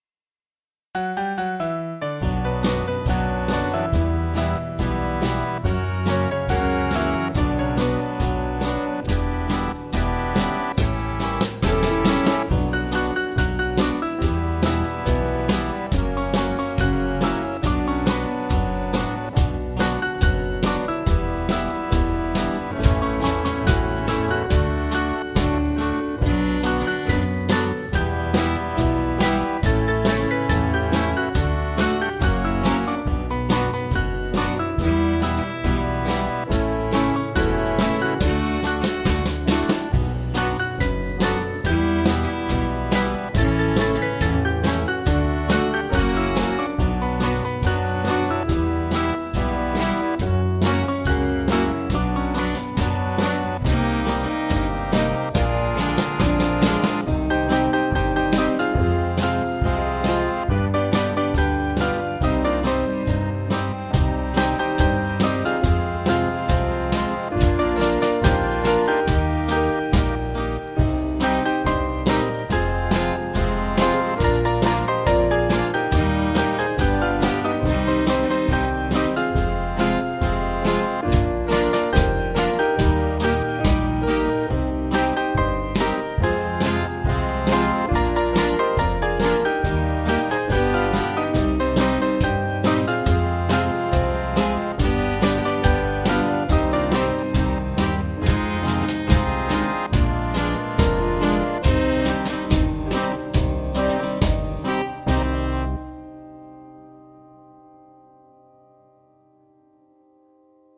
Small Band
Jewish Feel